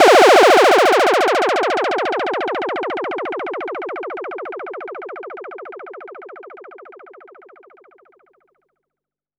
Shockwave.wav